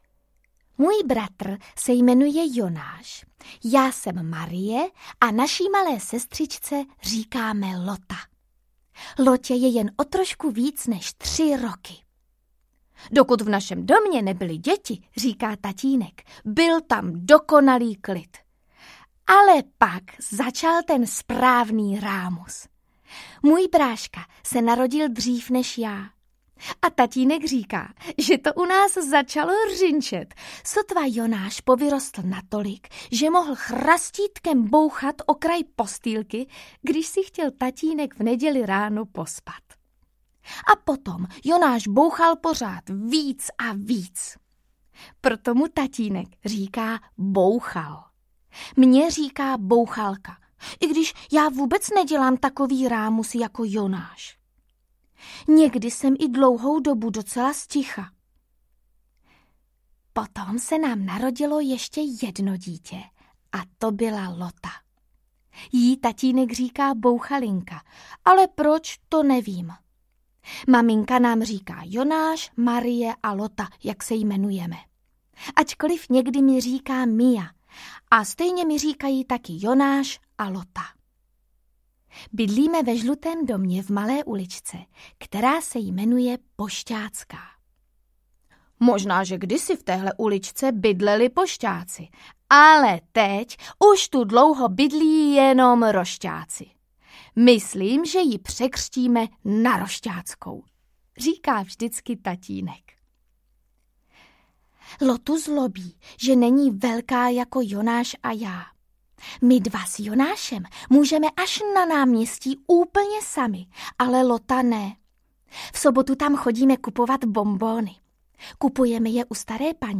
Lotta z Rošťácké uličky audiokniha
Ukázka z knihy